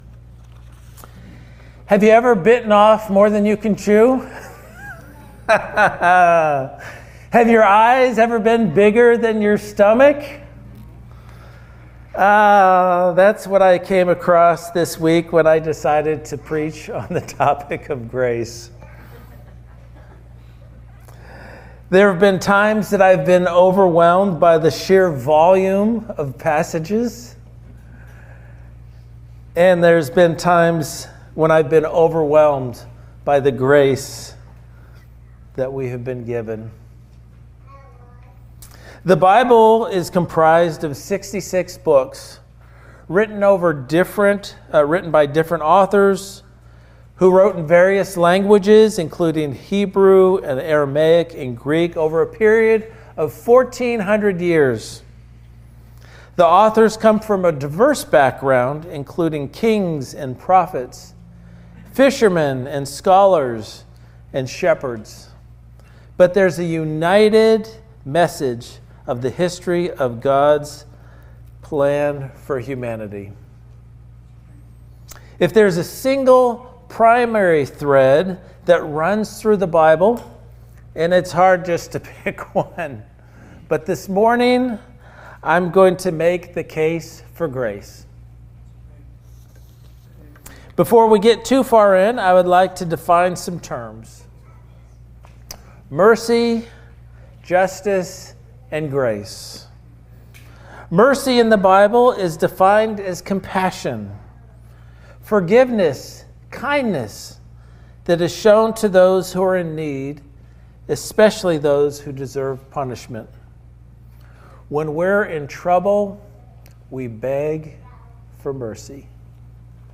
Guest Speaker, Standalone Sermon